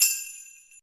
soft-hitwhistle.mp3